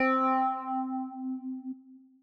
Organ - 80's.wav